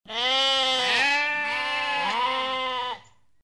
Flock.mp3